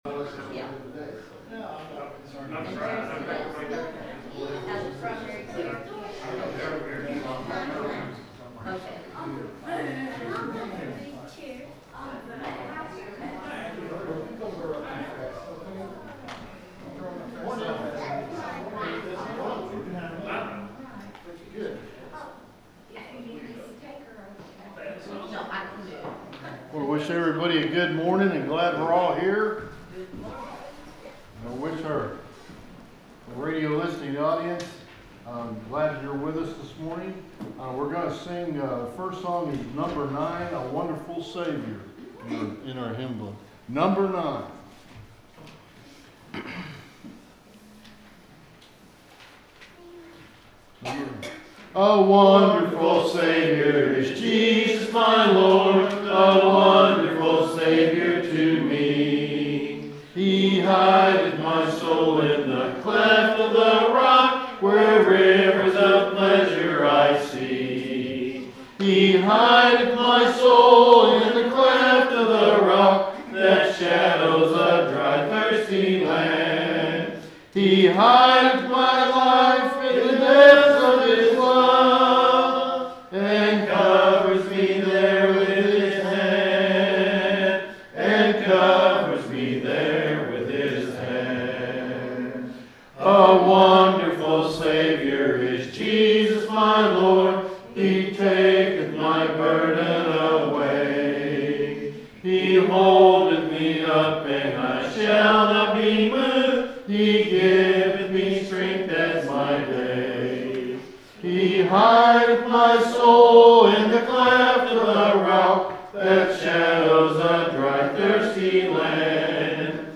The sermon is from our live stream on 11/30/2025